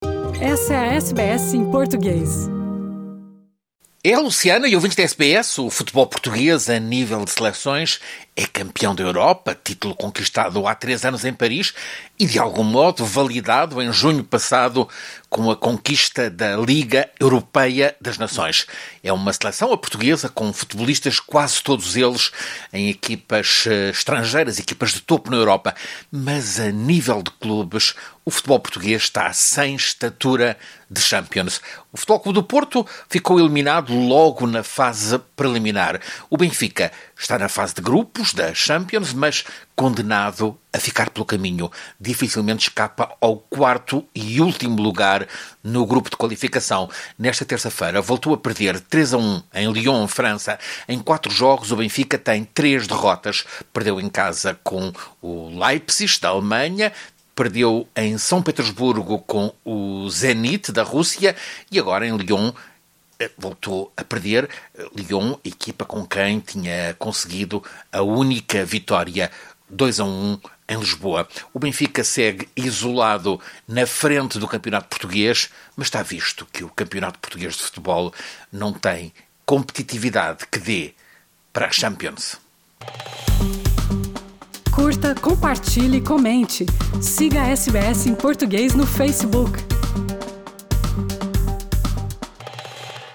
reporta, desde Lisboa.